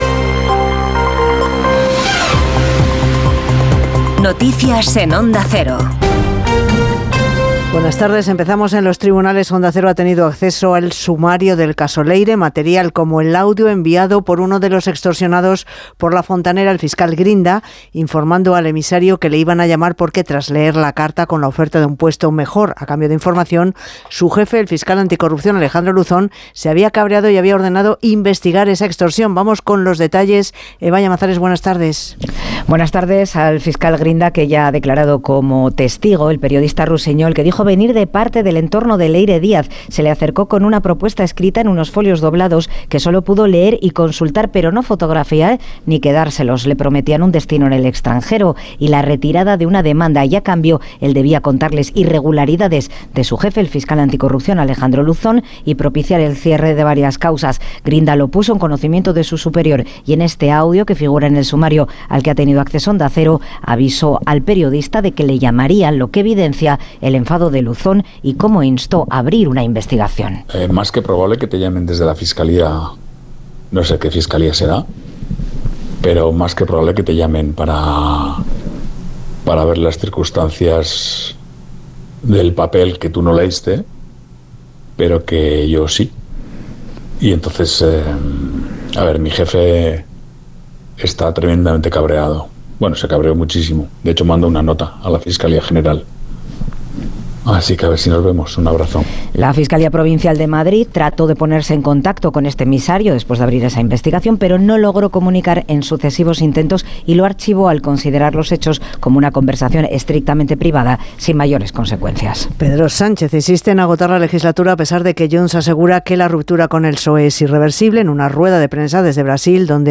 Conoce la ultima hora y toda la actualidad del dia en los boletines informativos de Onda Cero.